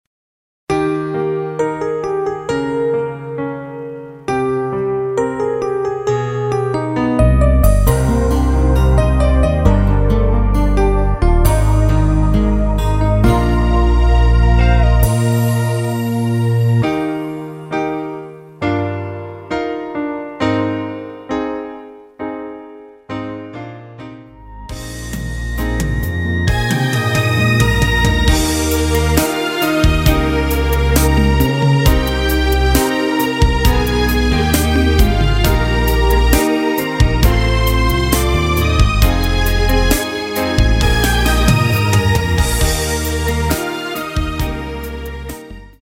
MR입니다. 발매일 1996.05 키 Eb 가수
원곡의 보컬 목소리를 MR에 약하게 넣어서 제작한 MR이며